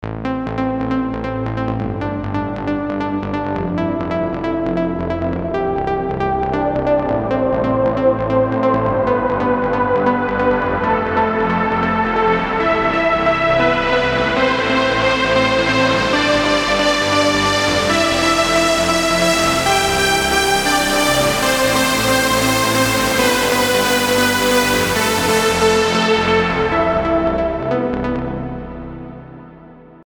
Trance песочница (крутим суперпилы на всём подряд)